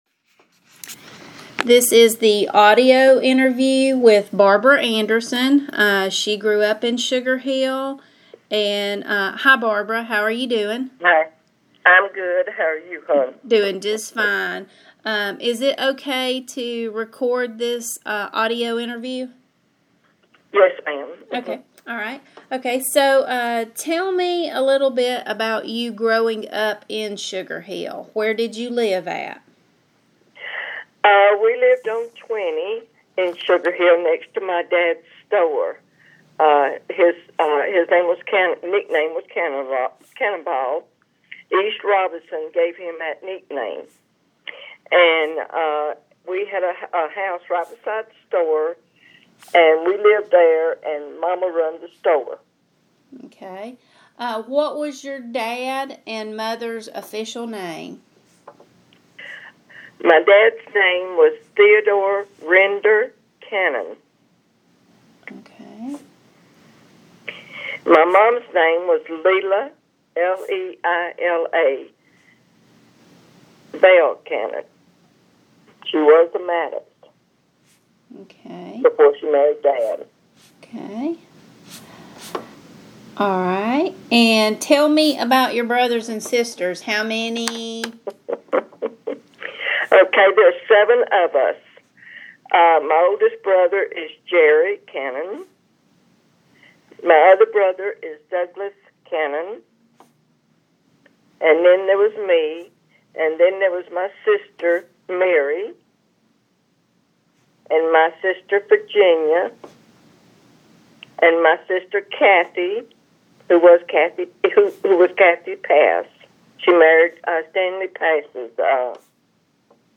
Oral histories
Oral History